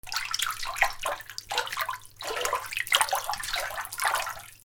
水音 水をかき回す
『チョロチョロパシャパシャ』